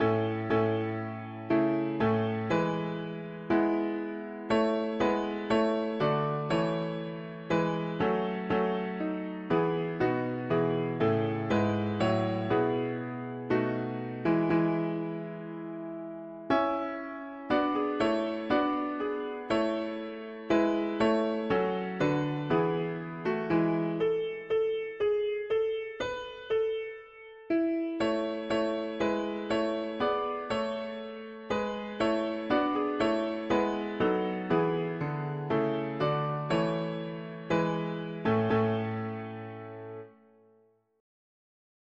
O come, let us ado… english christian 4part winter
Key: A major Meter: irregular